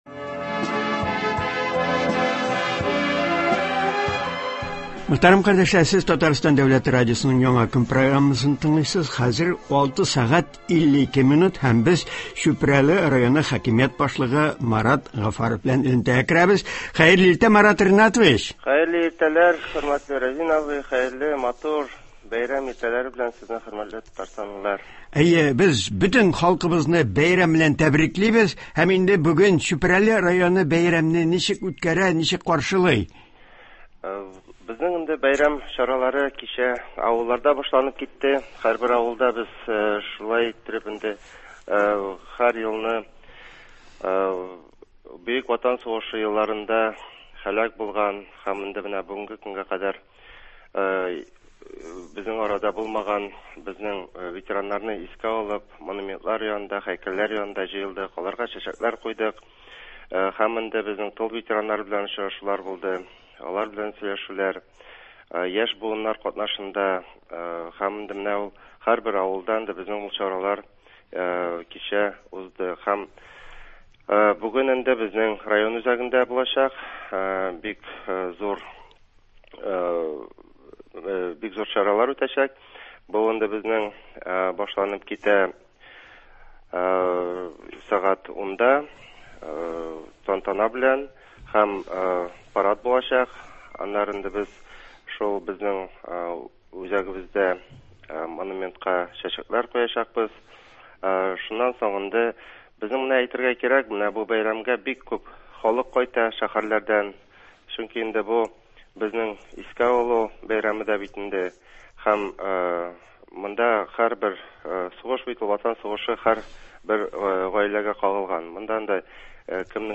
әңгәмәләр тәкъдим ителә, сугыш чоры җырлары яңгырый.